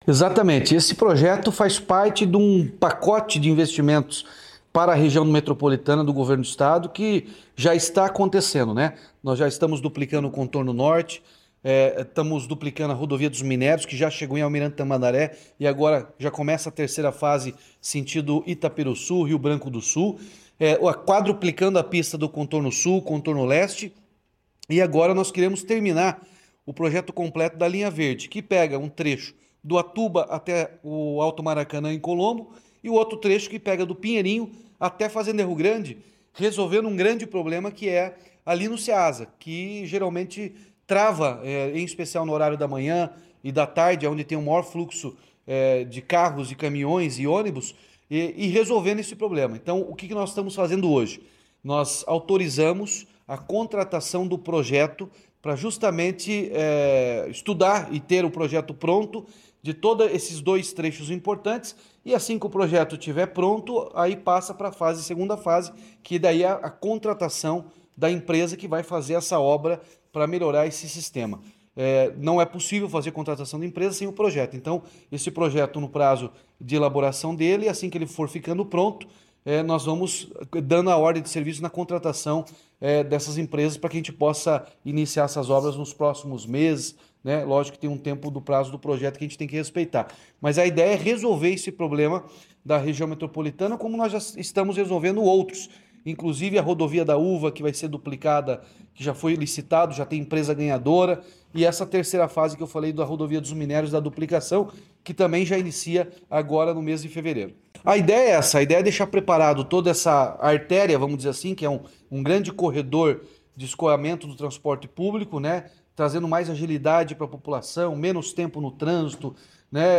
Sonora do governador Ratinho Junior sobre o anúncio de corredor de ônibus para conectar Colombo, Curitiba e Fazenda Rio Grande